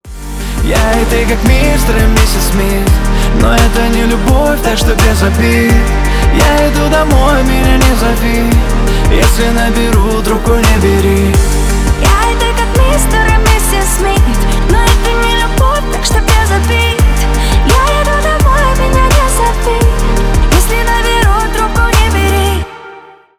Дуэт
Рэп